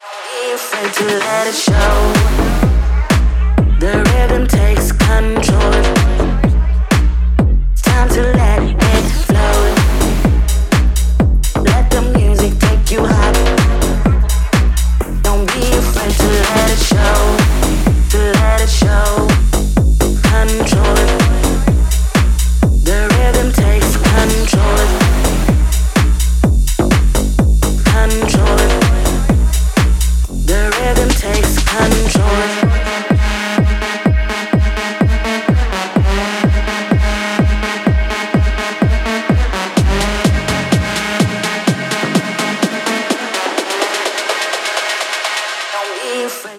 • Качество: 128, Stereo
громкие
мощные
басы
Tech House
G-House
клубная музыка
Для любителей клубной музыки в стиле G-House